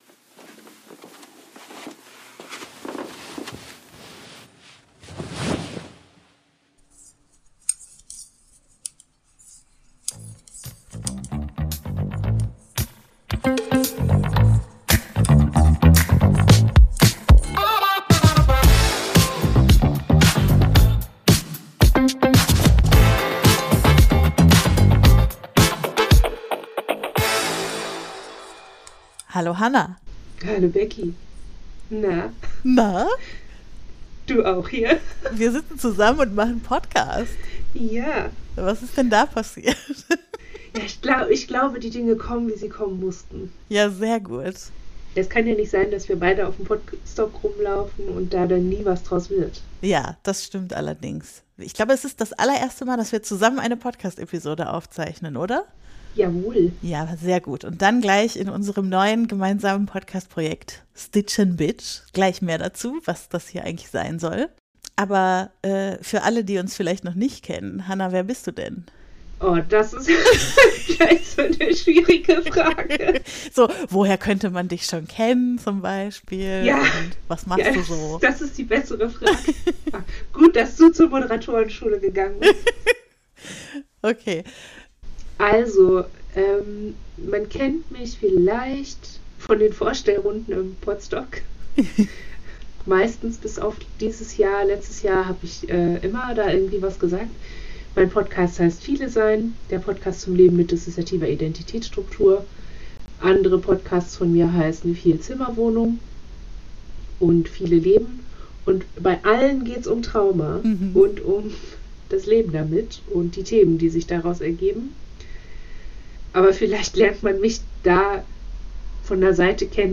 P.S.: Sound wird im Laufe der Episode besser, versprochen!